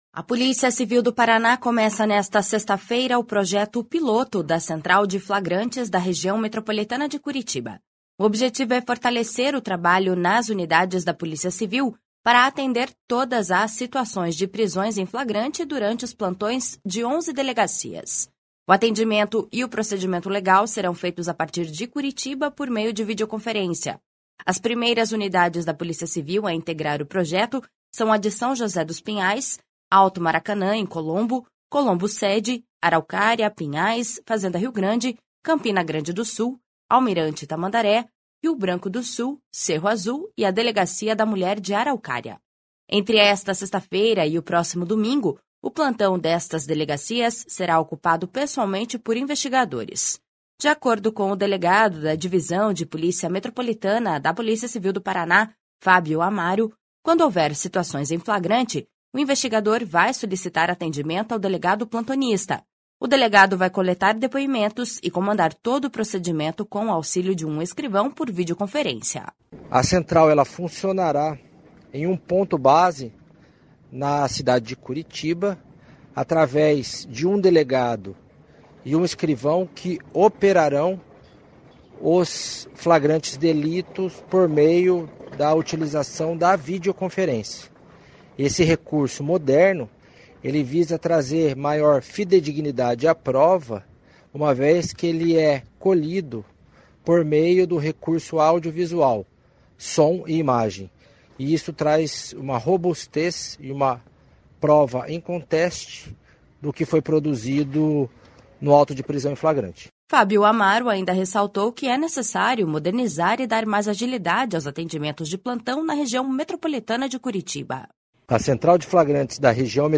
O projeto da Central de Flagrantes da Região Metropolitana de Curitiba foi estruturado nos últimos quatro meses, incluindo a capacitação de servidores e adequações nas unidades. (Repórter